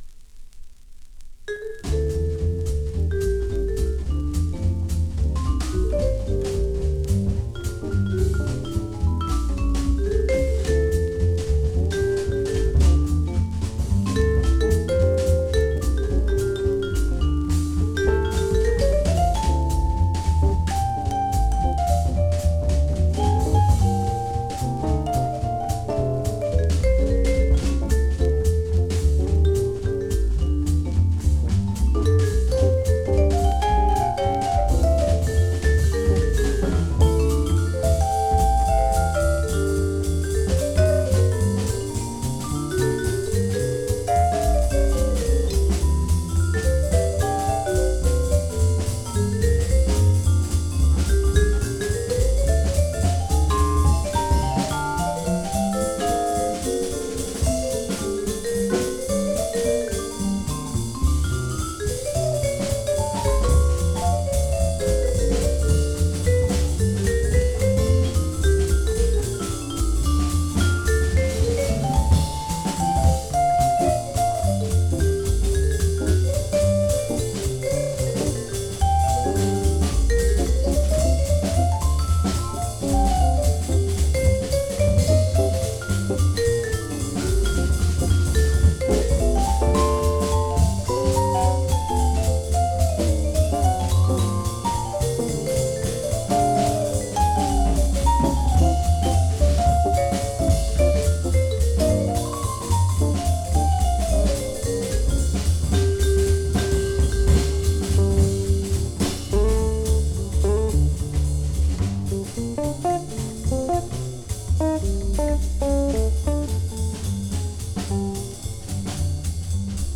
Recorded:  14-16 August, 1963 in New York City, NY
play it up-tempo, breezy and with no frills